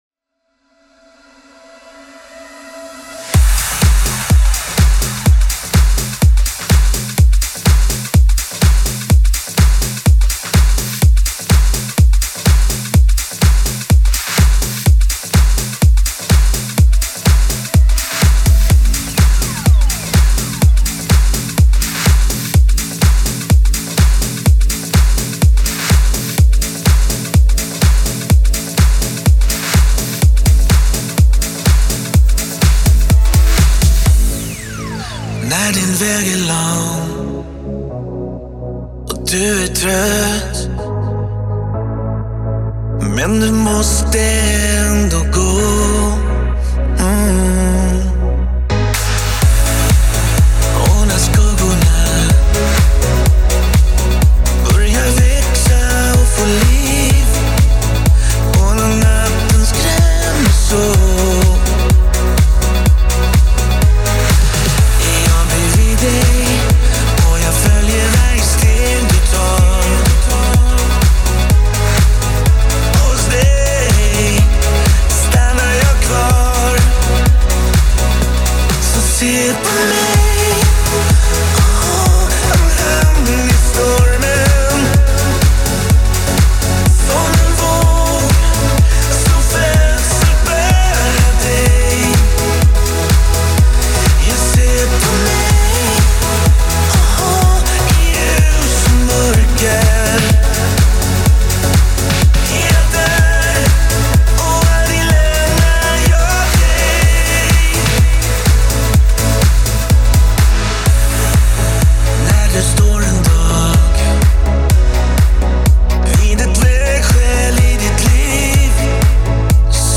• Ihopa mixad 2 st classic mix egen gjord.